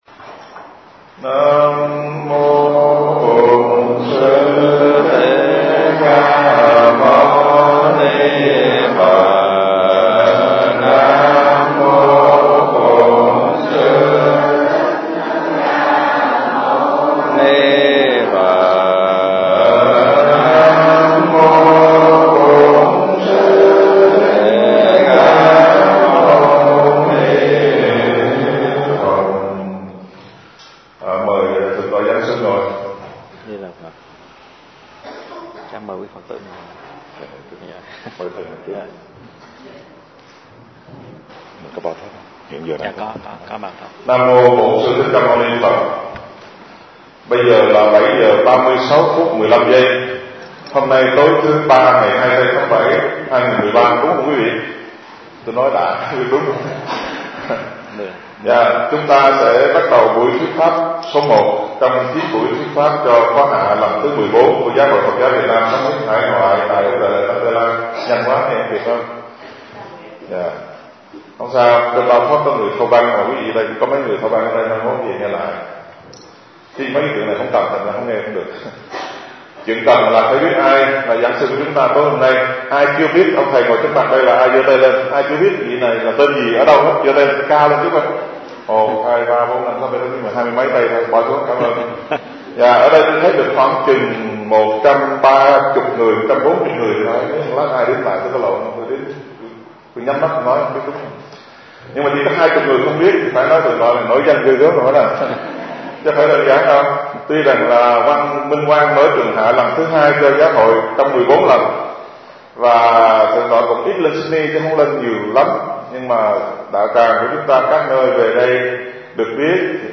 Kính bạch Sư Phụ, hôm nay chúng con được học về Thiền sư Thiền Nham (1093 - 1163). Ngài thuộc đời thứ 13, Thiền Phái Tỳ-Ni-Đa-Lưu-Chi. Pháp thoại hôm nay là bài giảng thứ 279 của Sư Phụ bắt đầu từ mùa cách ly do bệnh đại dịch covid 19 (đầu tháng 5-2020).